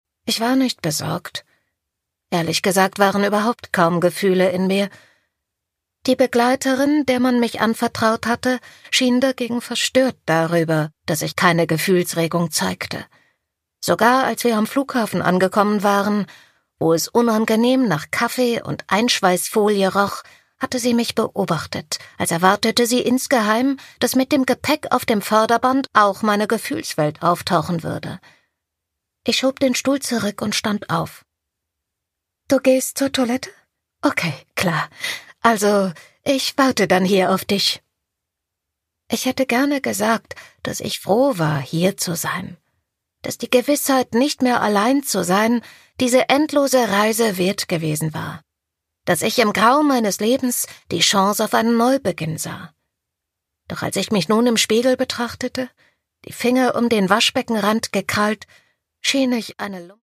Produkttyp: Hörbuch-Download
Man spürt zu jeder Sekunde, dass sie diesen poetischen Roman und seine sinnliche Sprache liebt.